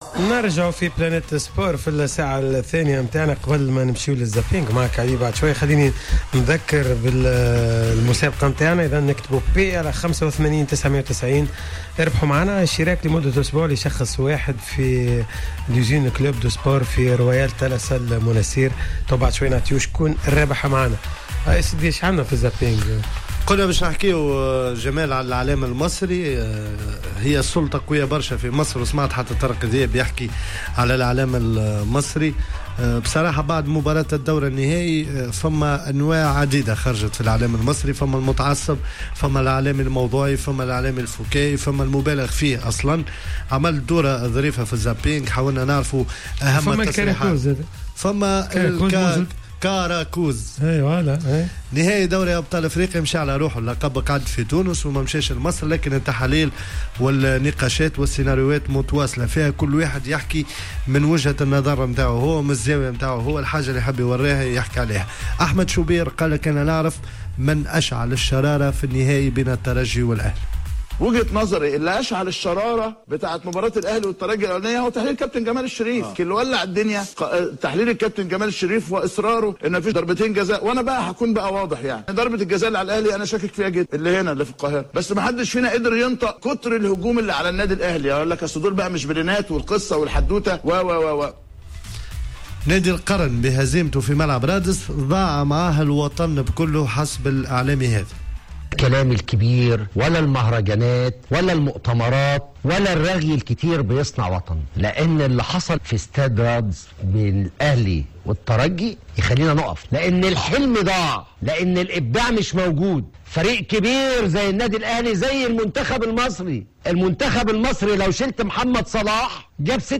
كما تدخل هاتفيا المحلل الفني طارق ذياب